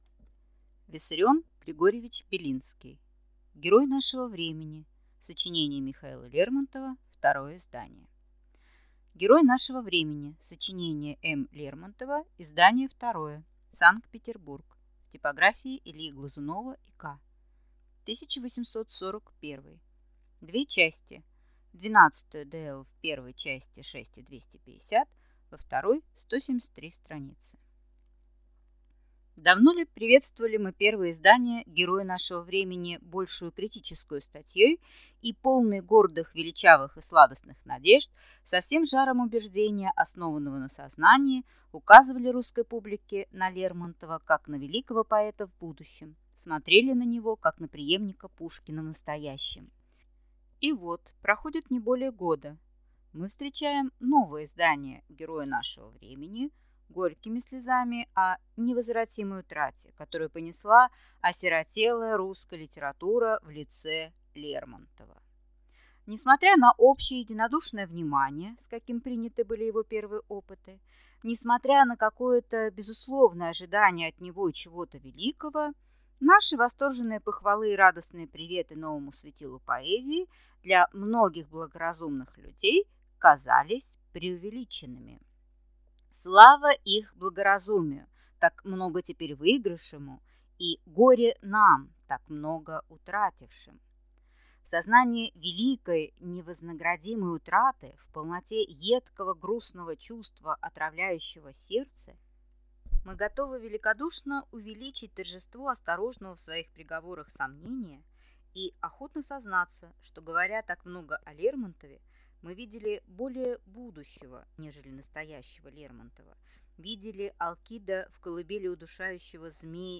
Аудиокнига Герой нашего времени. Сочинение М. Лермонтова. Издание второе | Библиотека аудиокниг